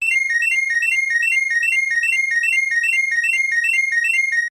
Sound effect from Super Mario RPG: Legend of the Seven Stars
SMRPG_SFX_Scarecrow_Bell.mp3